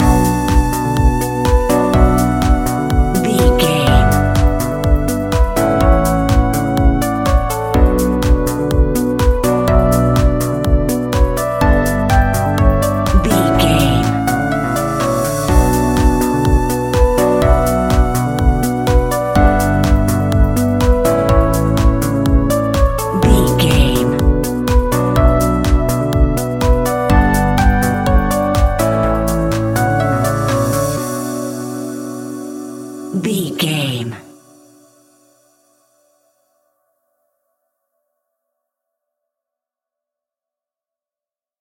Aeolian/Minor
groovy
uplifting
futuristic
driving
energetic
drum machine
synthesiser
house
electro house
funky house
synth leads
synth bass